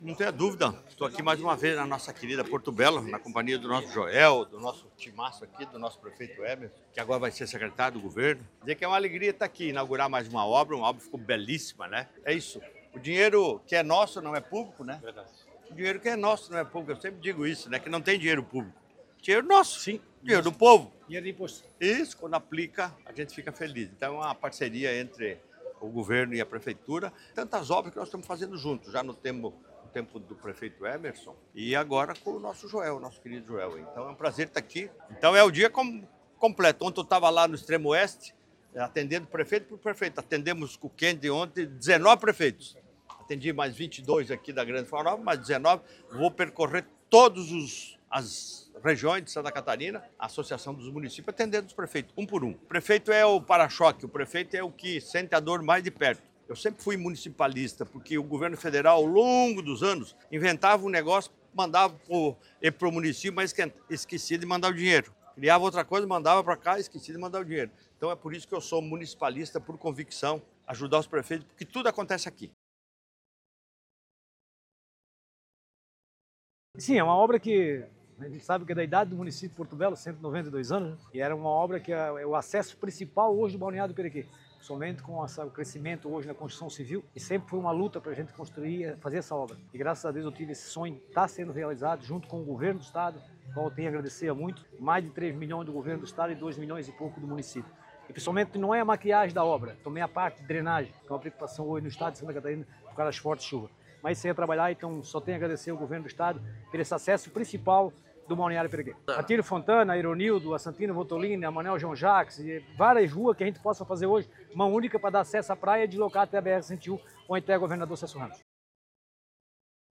SONORA – Jorginho Mello inaugura revitalização da principal avenida de acesso à Praia do Perequê em Porto Belo
O governador Jorginho Mello destacou a importância de ajudar os prefeitos, os que estão na ponta:
O prefeito Joel Lucinda ressaltou a importância da obra para a cidade:
O deputado estadual e ex-prefeito municipal da cidade, Emerson Stein, valorizou a presença do governador nos municípios: